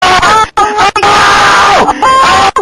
MUM GET THE CAMERA GUYS SCREAM
Category: Sound FX   Right: Personal